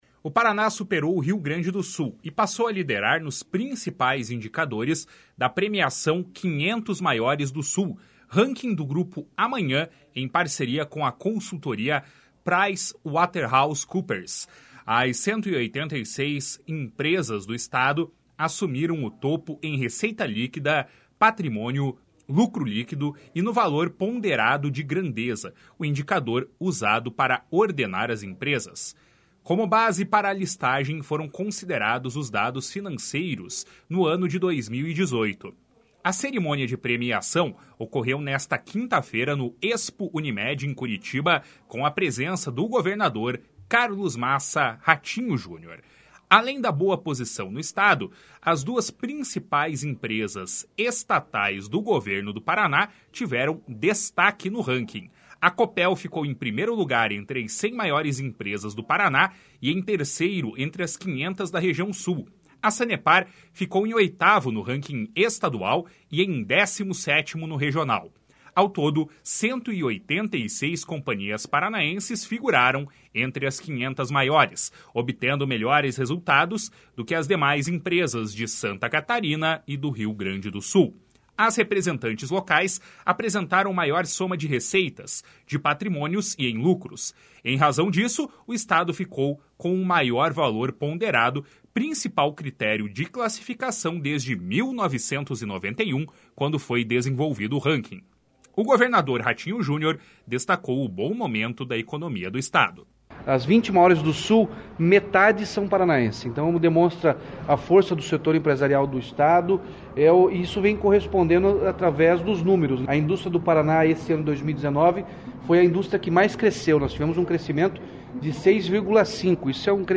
A cerimônia de premiação ocorreu nesta quinta-feira, no ExpoUnimed, em Curitiba, com a presença do governador Carlos Massa Ratinho Junior.
O governador Ratinho Junior destacou o bom momento da economia do Estado.